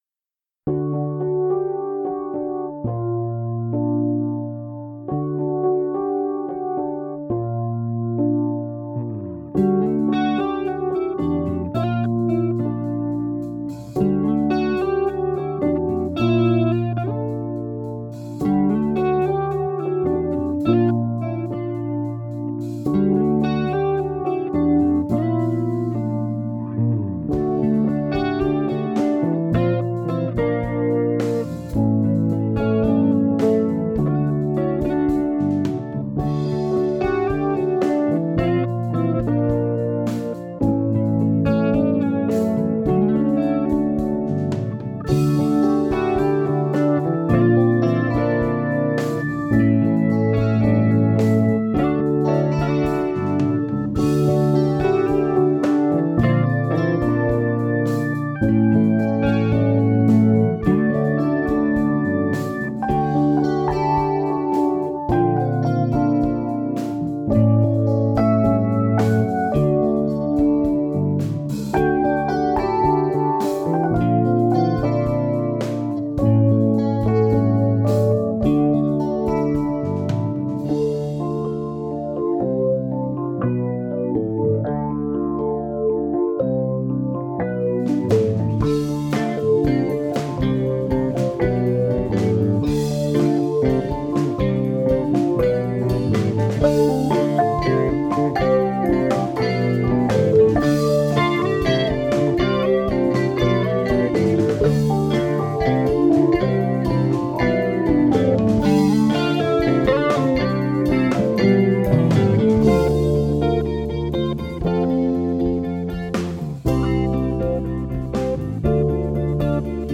Some old demo tracks (supposed to be gathered in our second demo, “No One Came Back”), reloaded with a couple of changes: new drums tracks, new mix and a few edits from 2015 – still to be recorded properly with a click one day though!
• Bass Guitar: Fender Jazz Bass (with Roland V-Bass for FX).
• Drums: Roland V-Drums triggering the Addictive Drums plug-in, by XLN Audio.